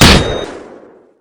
snd_jack_turretmissilelaunch_close.wav